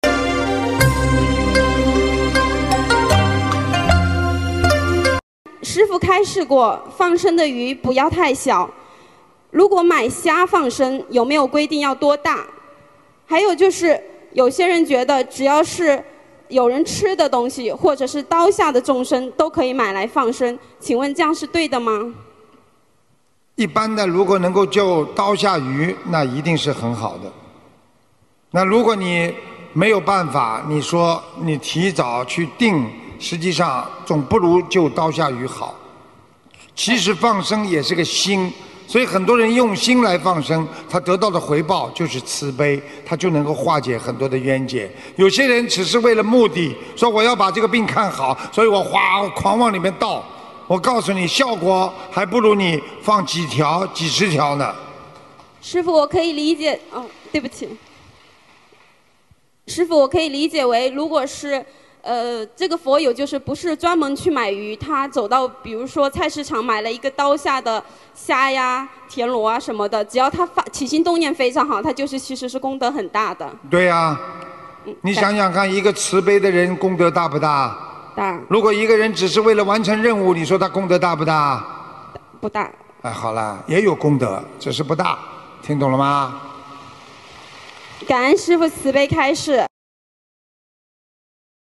音频：要用心放生！提早订鱼放生不如救刀下鱼好！为了完成任务放生！功德不大！2019年10月19日马来西亚吉隆坡！世界佛友见面会提问